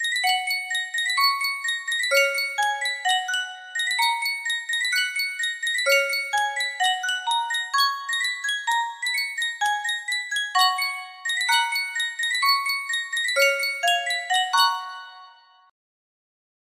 Yunsheng Music Box - Big Rock Candy Mountain 6450 music box melody
Full range 60